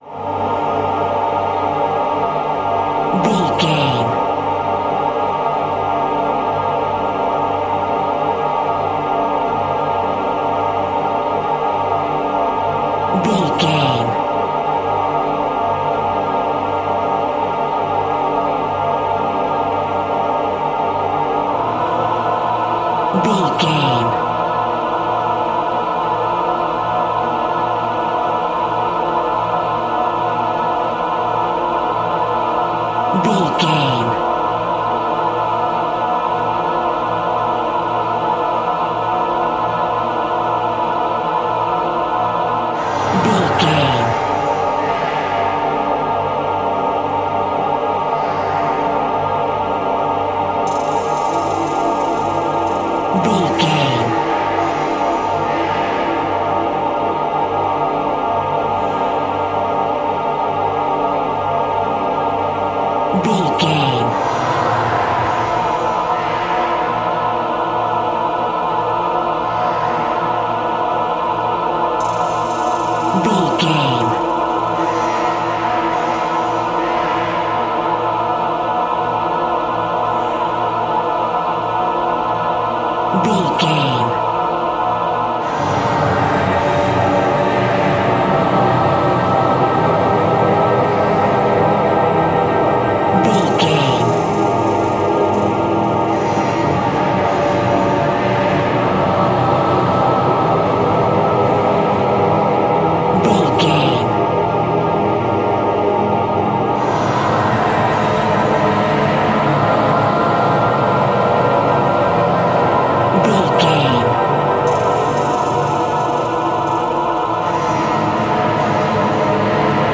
Atonal
ominous
haunting
eerie
synthesizer
creepy
horror music
Horror Pads
horror piano